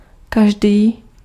Ääntäminen
IPA : /iːt͡ʃ/